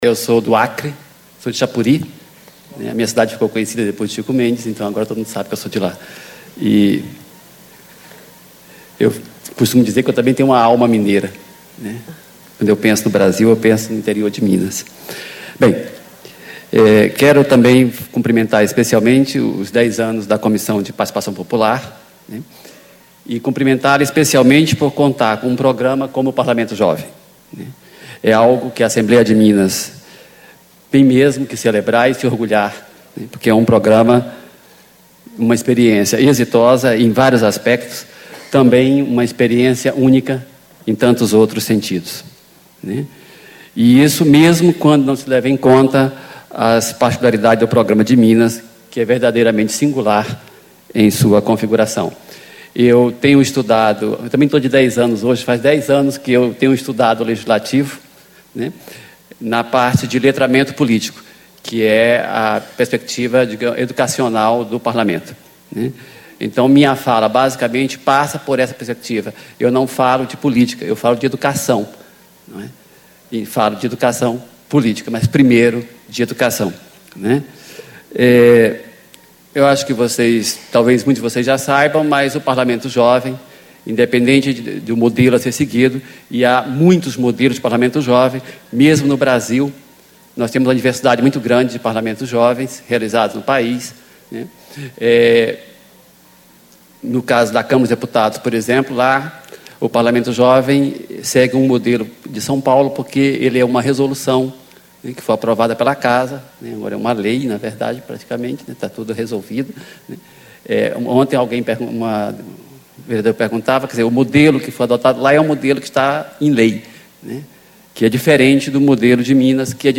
Palestra
Ciclo de Debates 10 anos da Comissão de Participação Popular
Discursos e Palestras